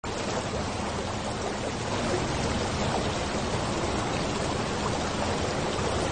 riverwater.mp3